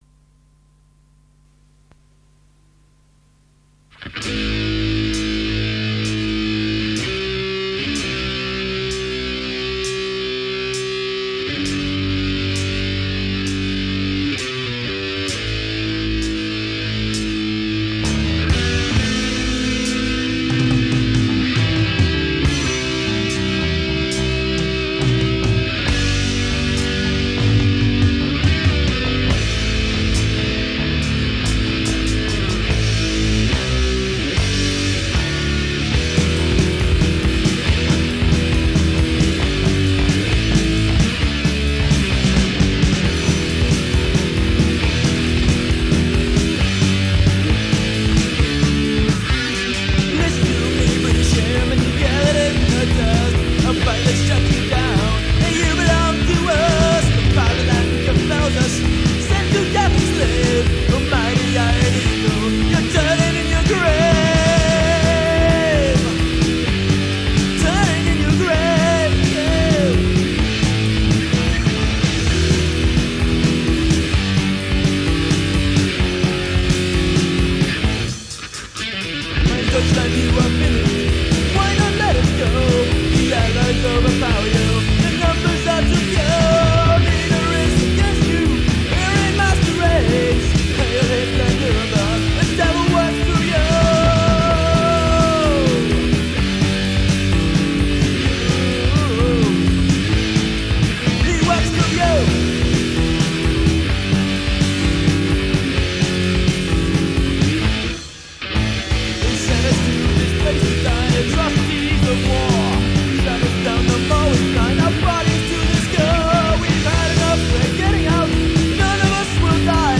Metal lives on.